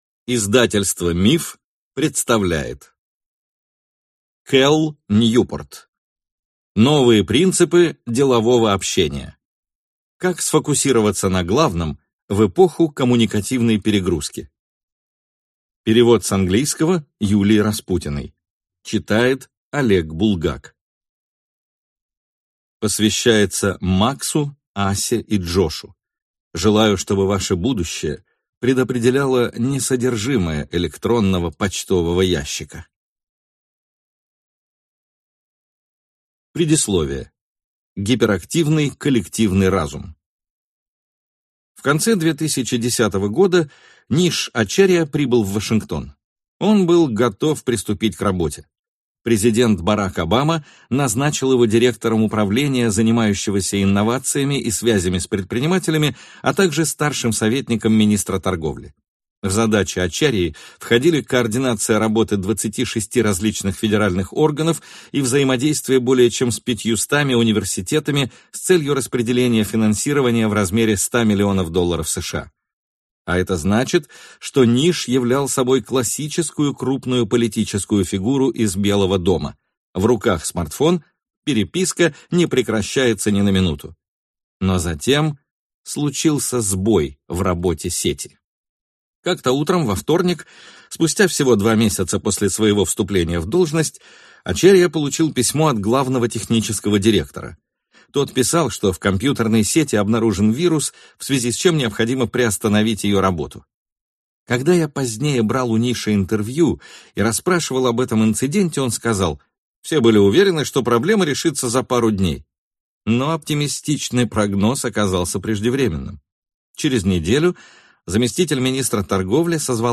Aудиокнига Новые принципы делового общения. Как сфокусироваться на главном в эпоху коммуникативной перегрузки